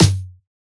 drum-hitclap.wav